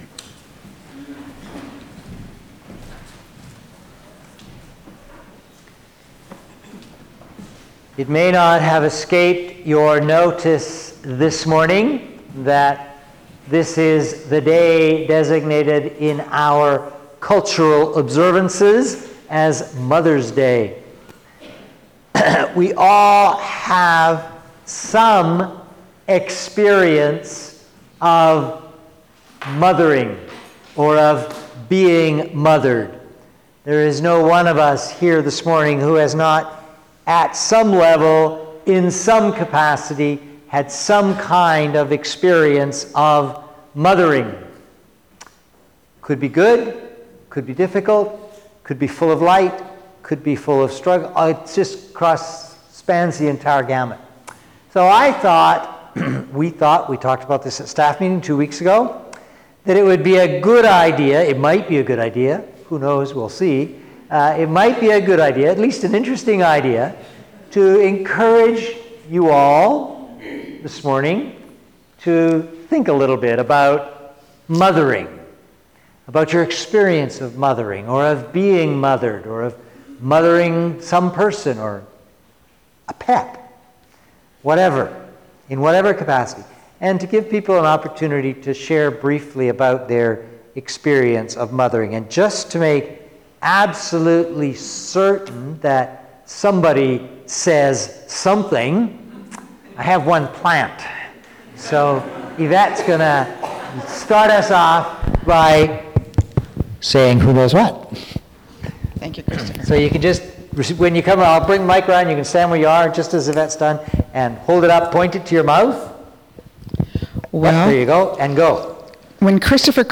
Sermons | St Philip Anglican Church
Members of the congregation shared their thoughts on mothers and mothering.